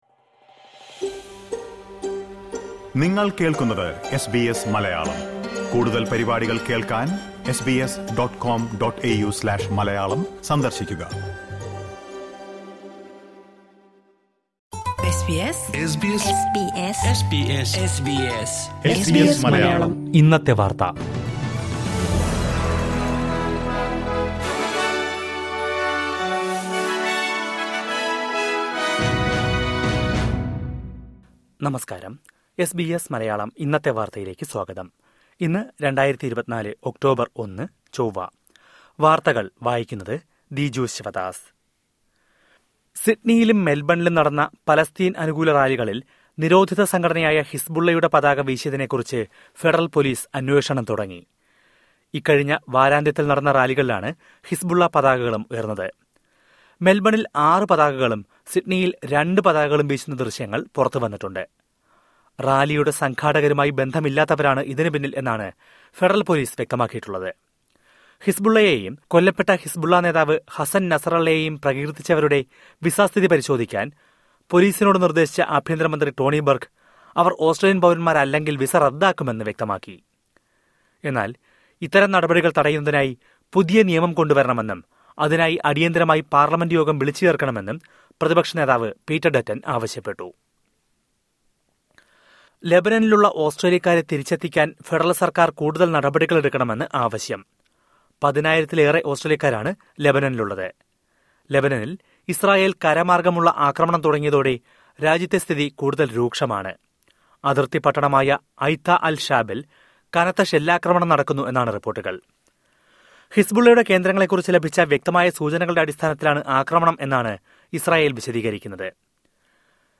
2024 ഒക്ടോബര്‍ ഒന്നിലെ ഓസ്‌ട്രേലിയയിലെ ഏറ്റവും പ്രധാന വാര്‍ത്തകള്‍ കേള്‍ക്കാം...